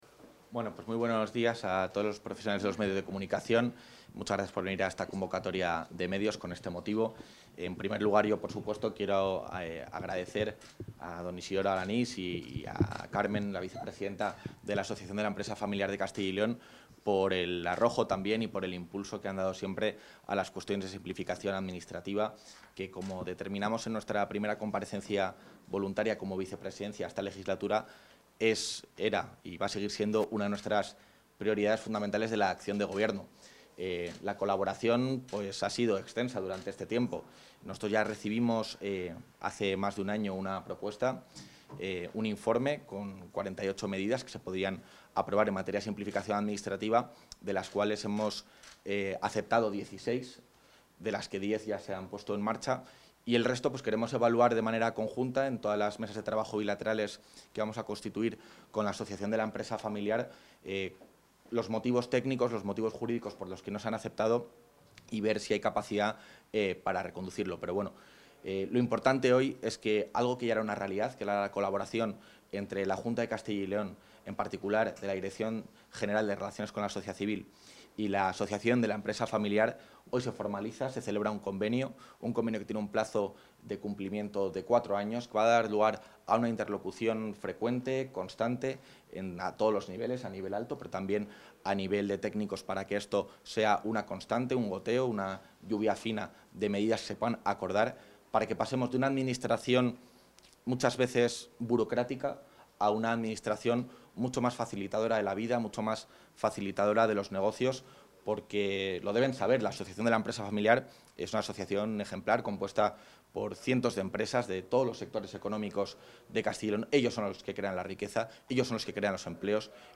Intervención del vicepresidente.